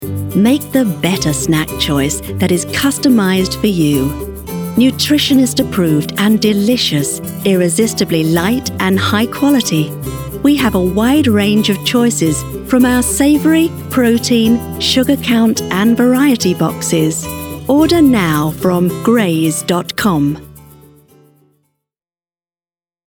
TV and Radio Commercials
Greys Voice Over